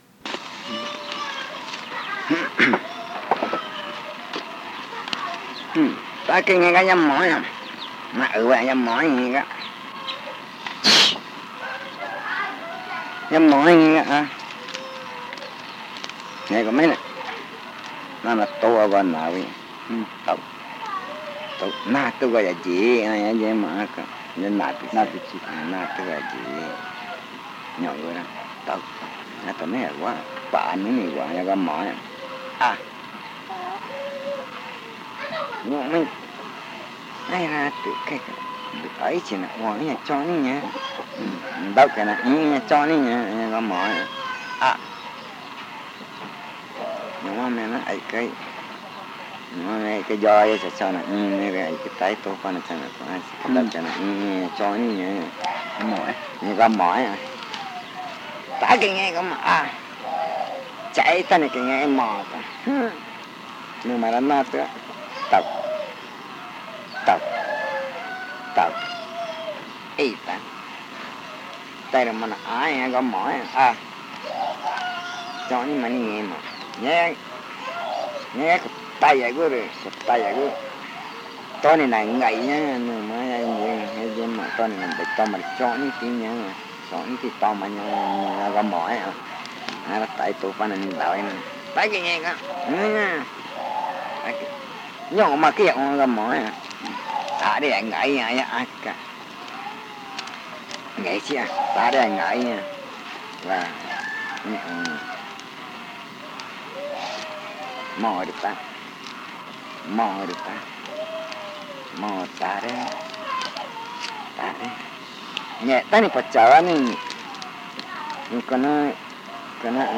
Se escribió en el casete que este contiene la historia de Moe y una historia de enfermedad. El audio subido contiene los lados A y B del casete, el segundo lado presenta problemas de grabación.